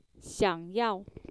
so even the male dialogue has female voice.